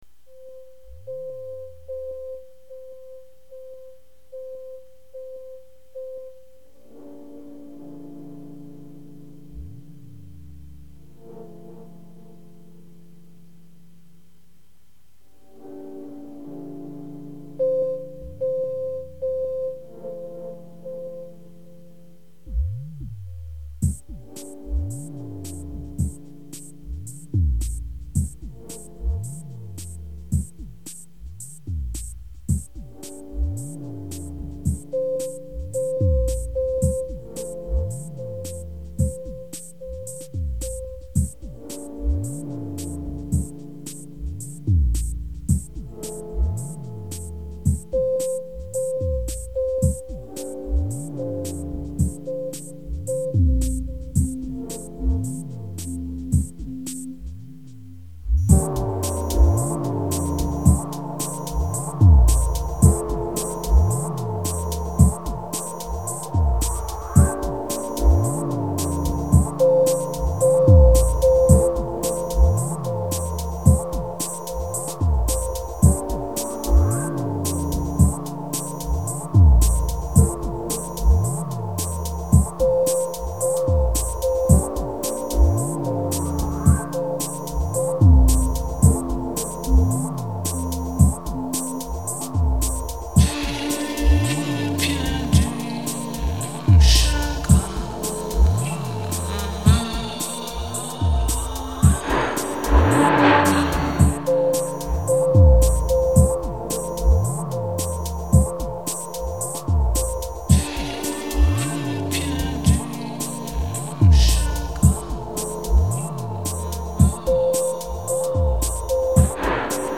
Tempo: 112 bpm